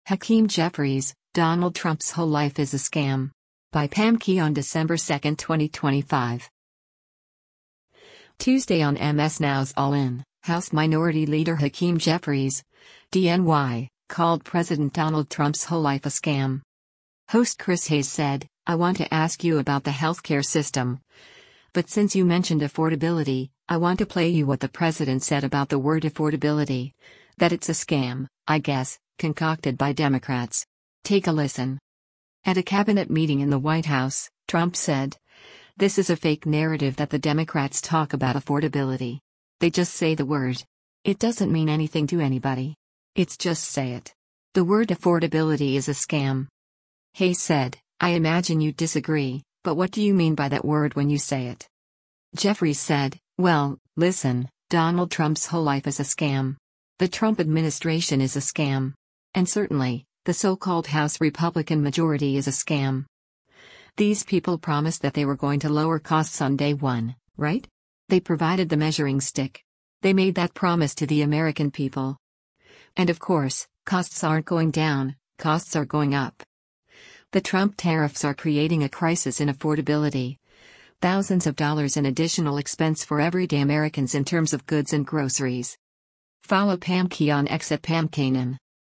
Tuesday on MS NOW’s “All In,” House Minority Leader Hakeem Jeffries (D-NY) called President Donald Trump’s whole life “a scam.”
Host Chris Hayes said, “I want to ask you about the health care system, but since you mentioned affordability, I want to play you what the president said about the word affordability, that it’s a scam, I guess, concocted by Democrats.